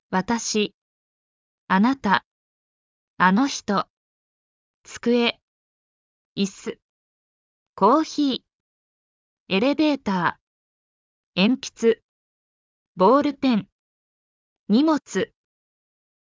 片仮名互換１０個字発音.mp3